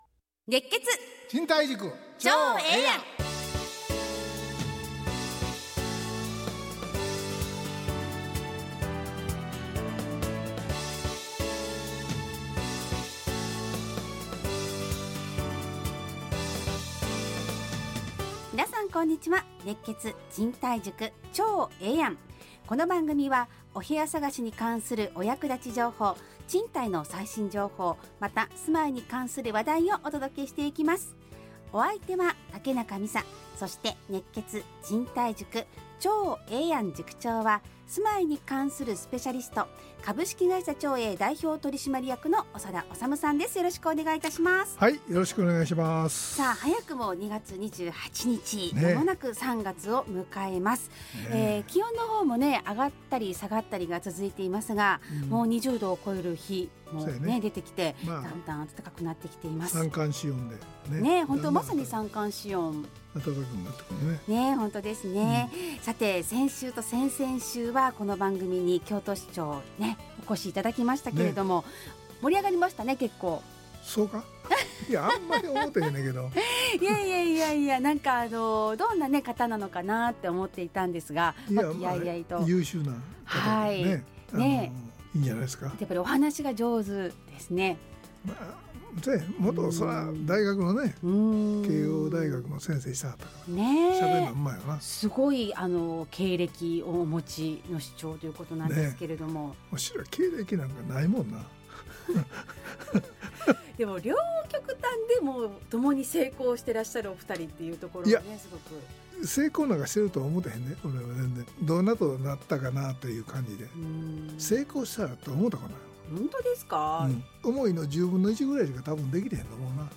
ラジオ放送 2025-03-03 熱血！